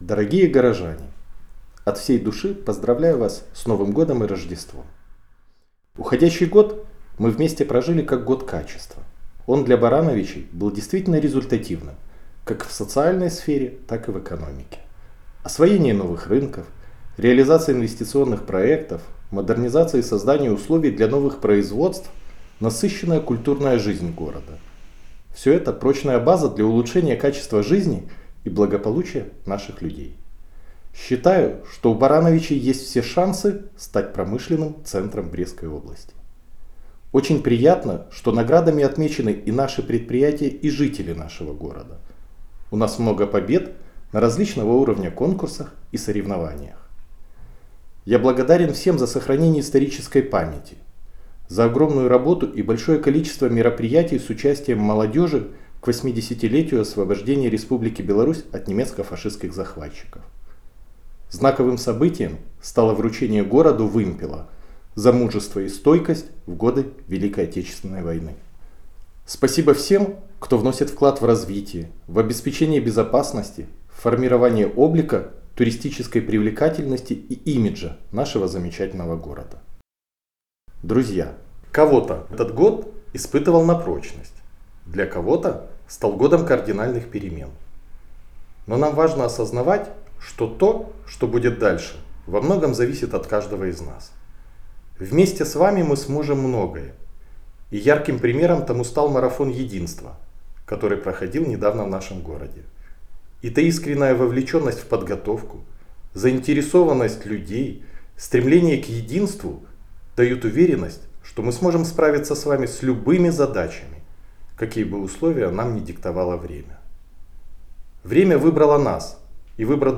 Новогоднее поздравление мэра города Барановичи Максима Антонюка